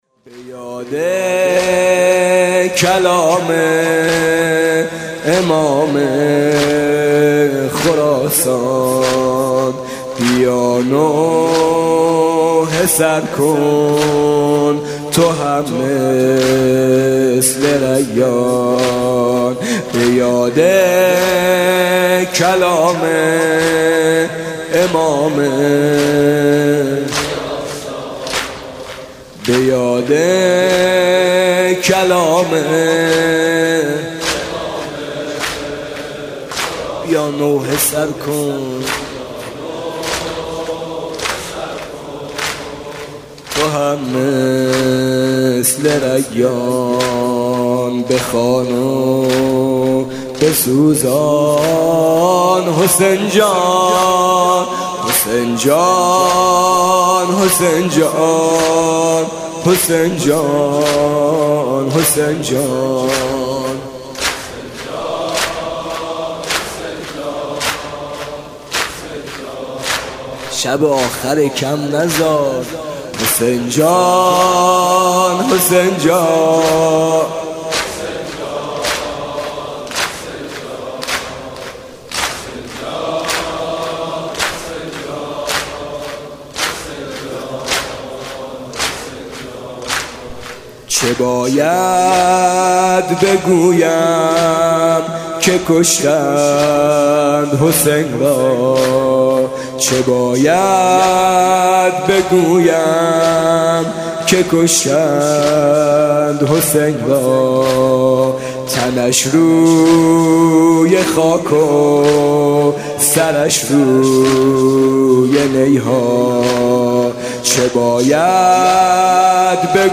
«محرم 1396» (شب یازدهم) زمینه: به یاد کلام امام خراسان